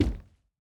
added stepping sounds
Plastic_005.wav